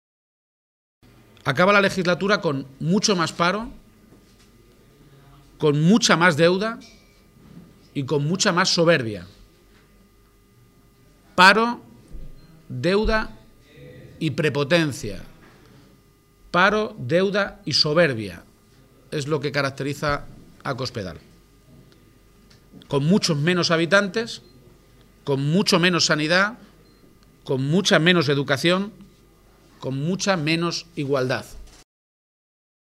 García-Page ha hecho esta afirmación en Guadalajara, donde ha mantenido un encuentro con medios de comunicación para avanzar las principales líneas de sus propuestas para Castilla-La Mancha, mientras Cospedal estaba en una reunión interna del PP convocada para analizar los desastrosos resultados de las elecciones andaluzas, “y convertida en el eje central de todos los problemas, también dentro de su partido”.